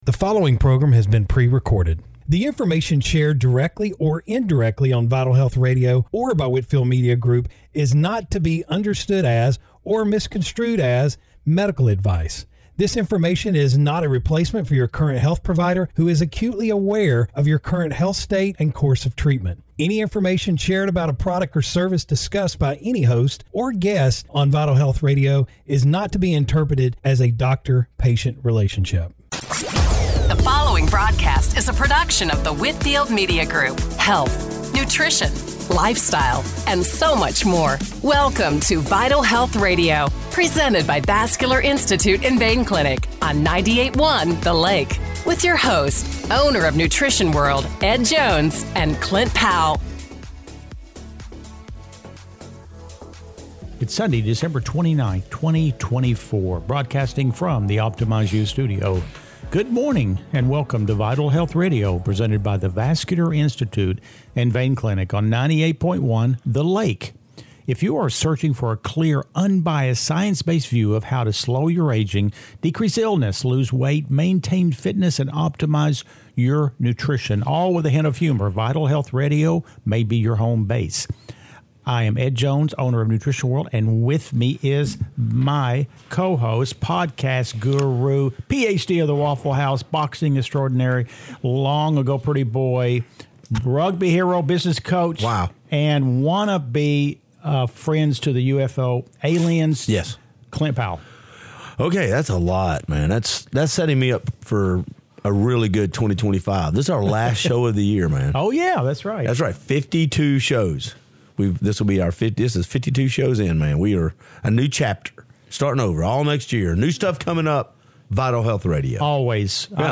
Radio Show – December 29, 2024 - Vital Health Radio